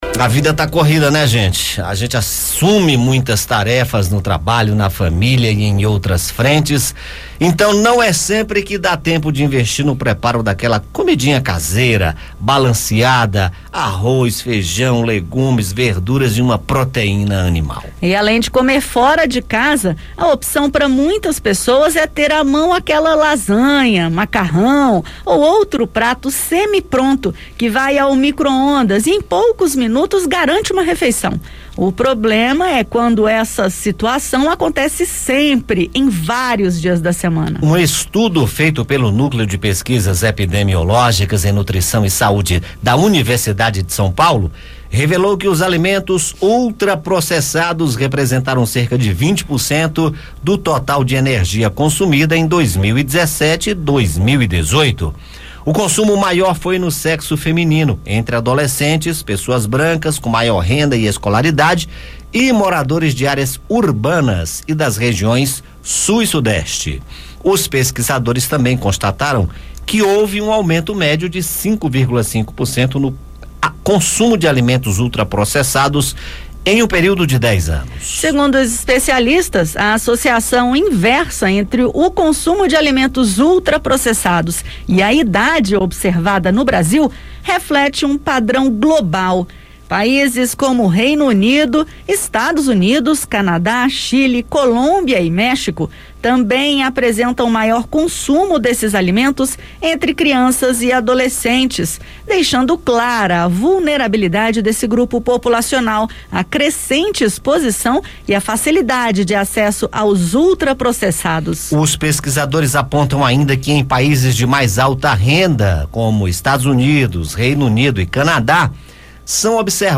Com a vida corrida, aumenta o consumo de alimentos ultraprocessados, hábito que provoca doenças como obesidade e desnutrição. O recomendado por especialistas para ter uma vida saudável é adotar uma alimentação rica em fibras e nutrientes. Ouça o bate-papo.